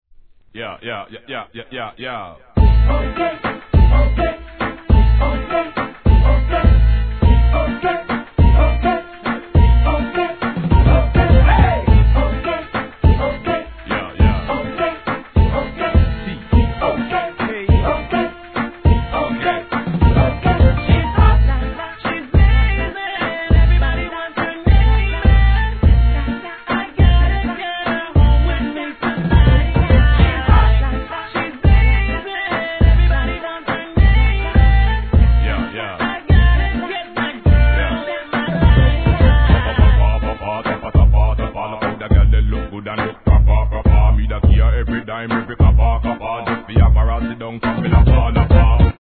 REGGAE
のフックがキャッチーなGALソング